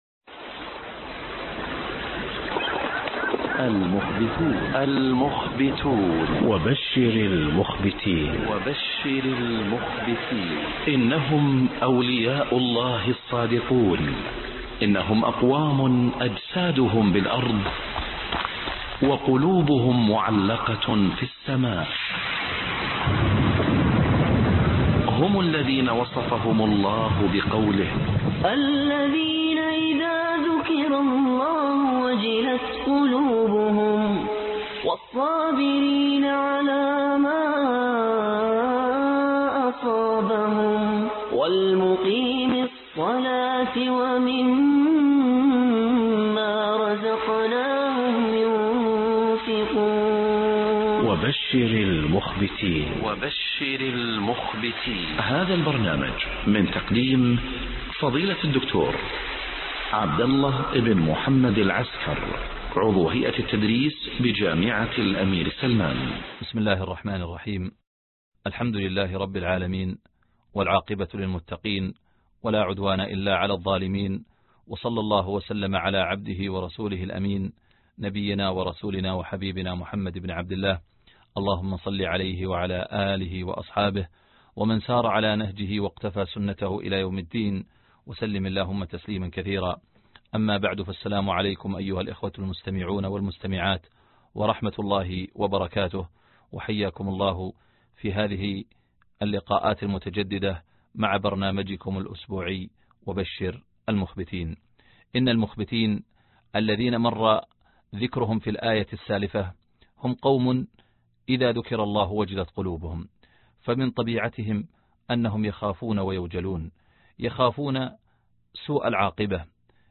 الدرس 23 العُجب (وبشر المخبتين)